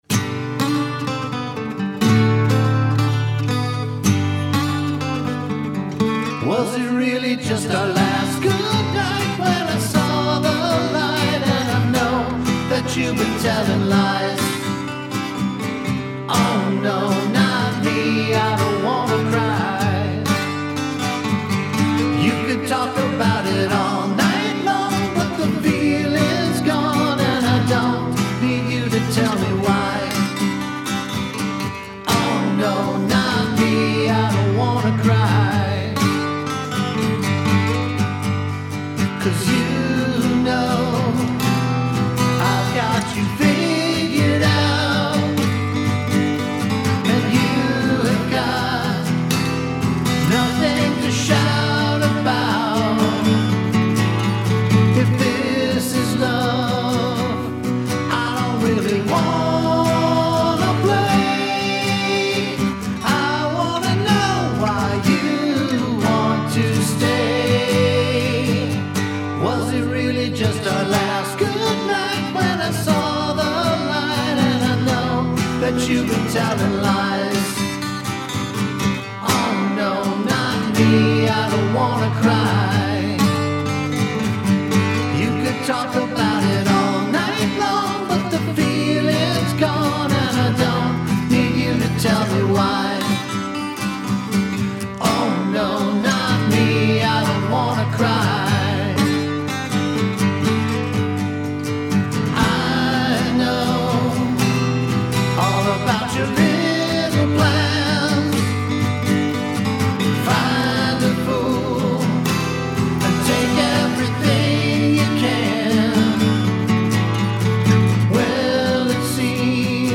Harmonies are really good.